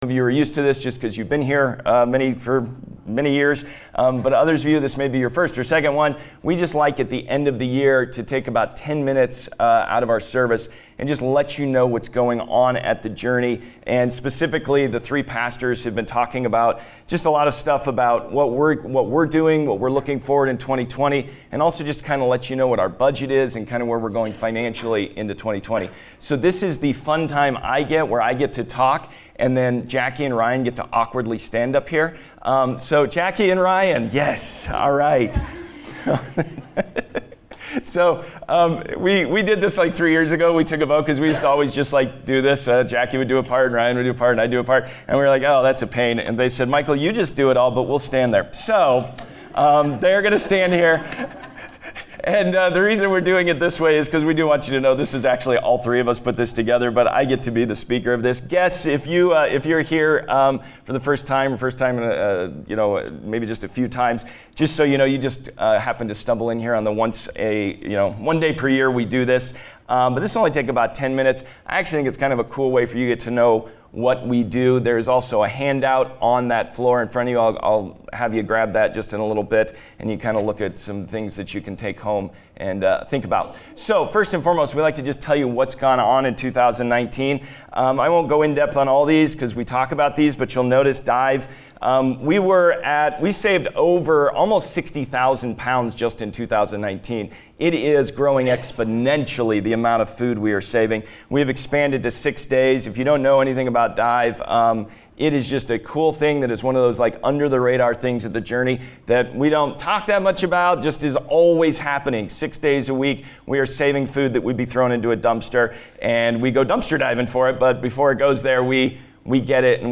This short talk recaps our budget and compassion service for 2024 and looks ahead to our goals for 2019.